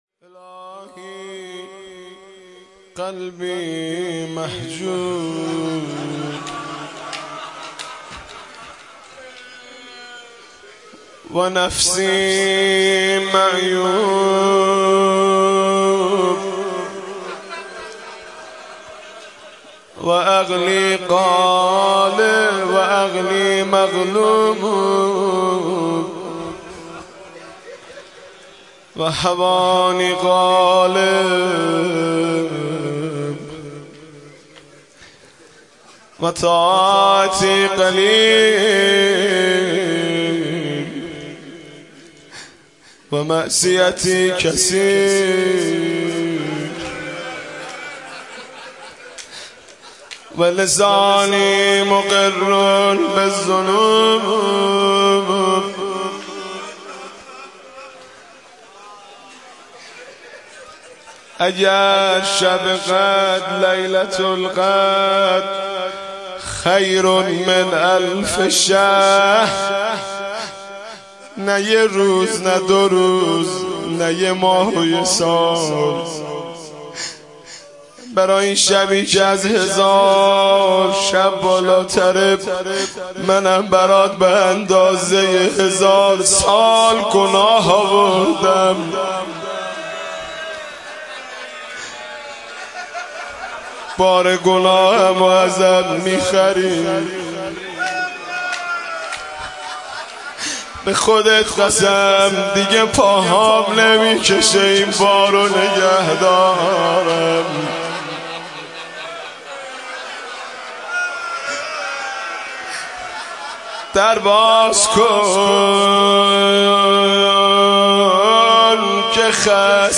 مداحی جدید حاج مهدی رسولی شب بیست و یکم رمضان ۹۷
مناجات و روضه